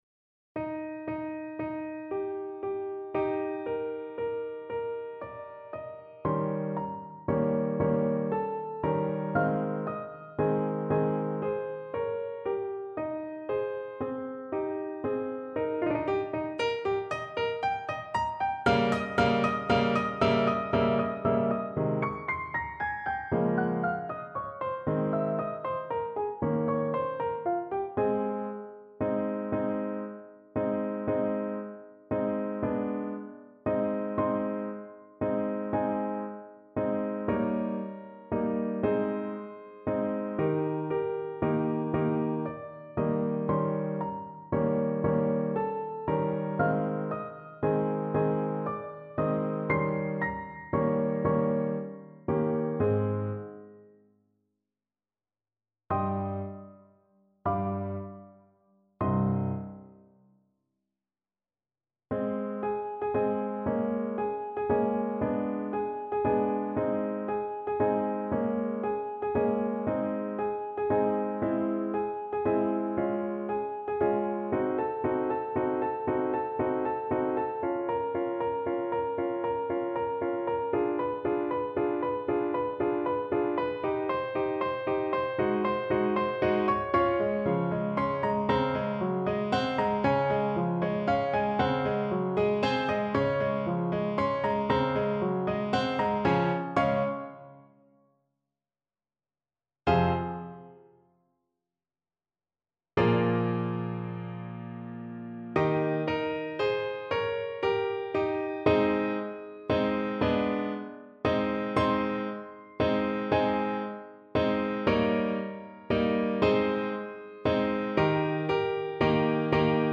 Free Sheet music for Trumpet
Ab major (Sounding Pitch) Bb major (Trumpet in Bb) (View more Ab major Music for Trumpet )
6/8 (View more 6/8 Music)
Andantino (=116) (View more music marked Andantino)
Trumpet  (View more Advanced Trumpet Music)
Classical (View more Classical Trumpet Music)